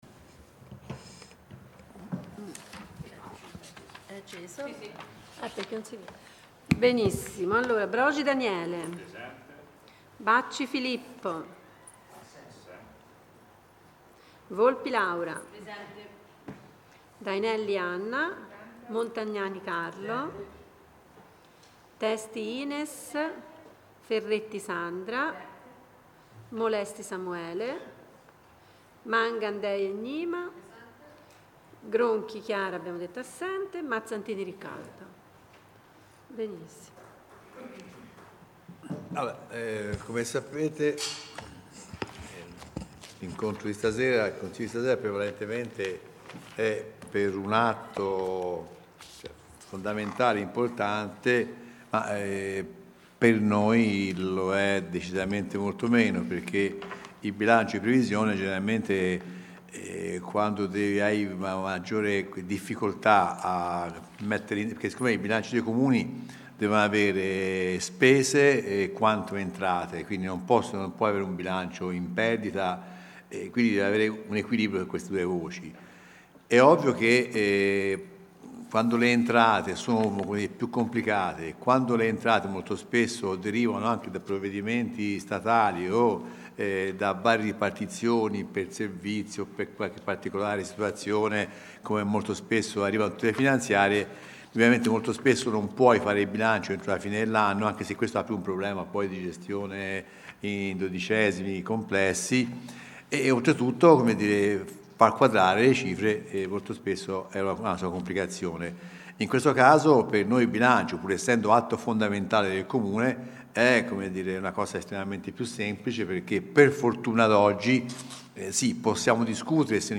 REGISTRAZIONI DEL CONSIGLIO COMUNALE DELL'ANNO 2025